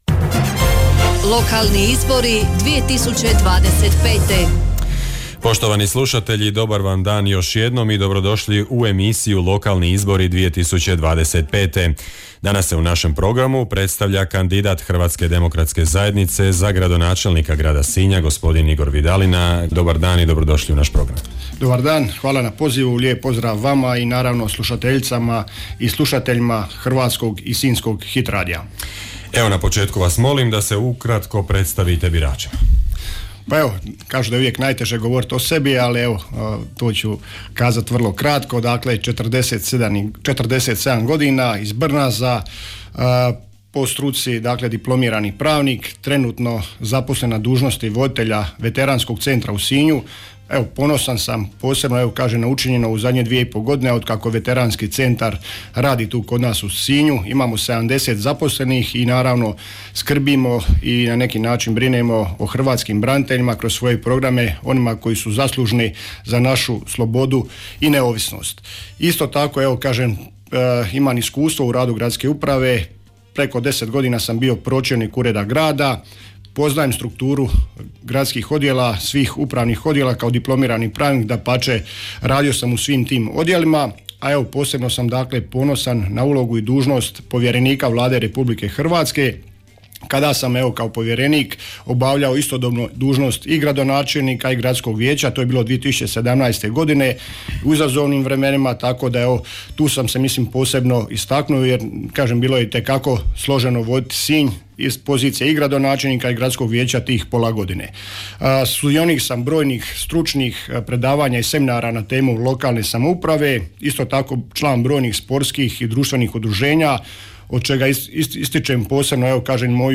Hit radio prati izbore u 7 jedinica lokalne samouprave (Grad Vrlika, Općina Dicmo, Općina Hrvace, Općina Dugopolje, Općina Otok, Grad Trilj, Grad Sinj). Sve kandidacijske liste i svi kandidati za načelnike odnosno gradonačelnike tijekom službene izborne kampanje imaju pravo na besplatnu emisiju u trajanju do 10 minuta u studiju Hit radija.